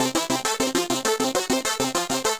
Index of /musicradar/8-bit-bonanza-samples/FM Arp Loops
CS_FMArp B_100-C.wav